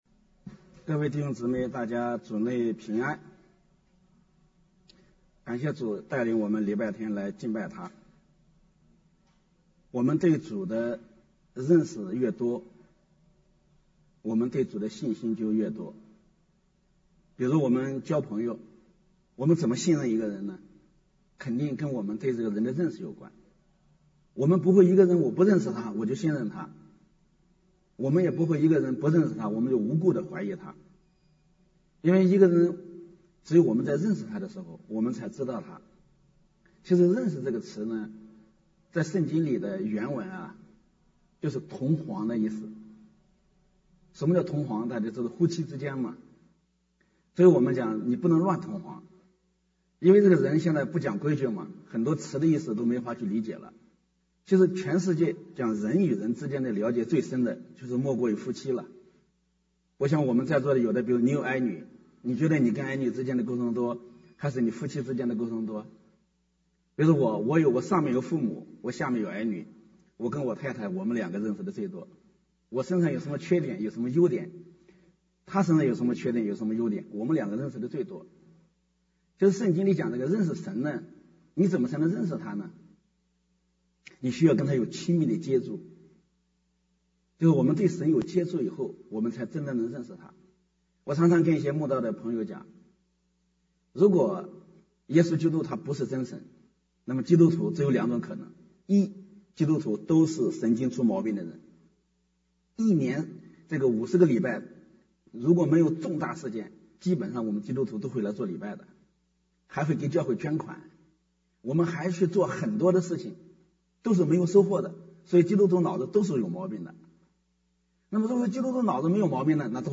10/16/2022 国语崇拜: 「正确的认识主耶稣」